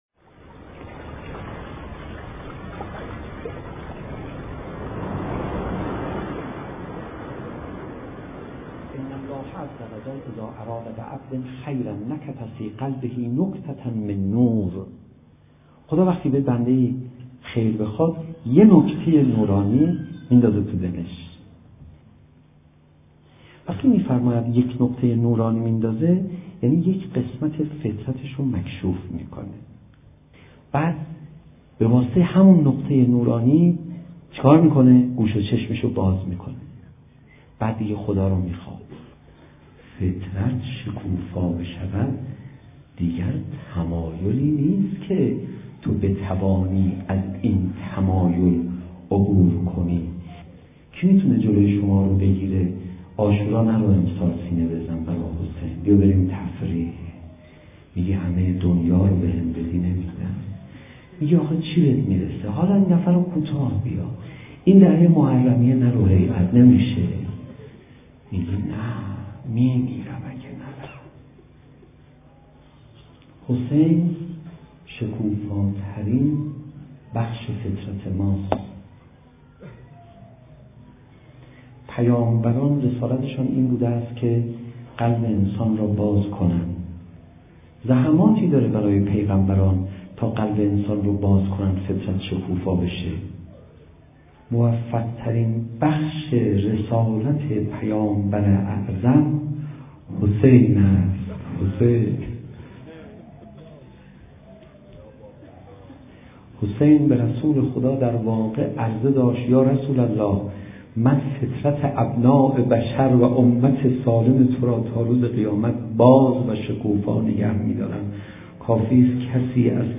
• زمان: ماه محرم ـ سال 91 ـ روز دوم محرم
• مکان: تهران ـ دانشگاه امام صادق(ع)
روضه